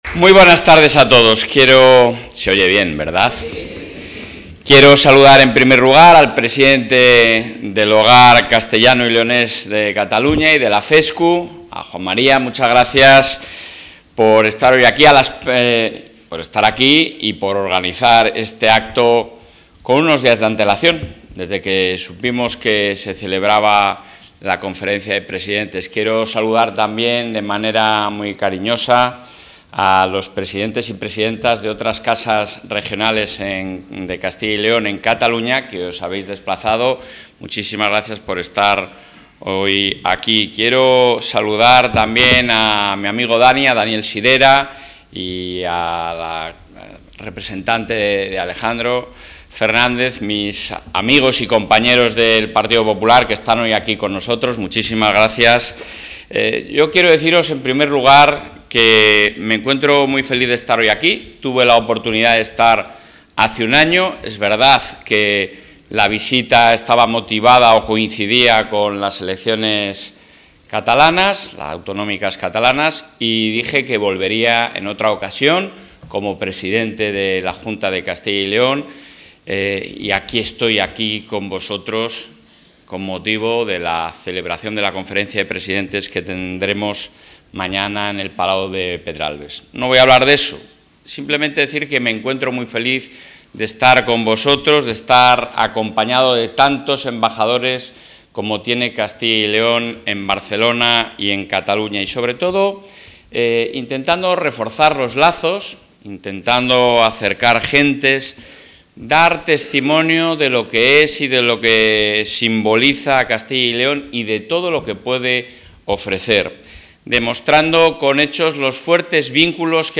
Intervención del presidente de la Junta.
El presidente de la Junta ha mantenido hoy en Barcelona un encuentro con los miembros de la Federación de Entidades Socioculturales de Castilla y León en Cataluña.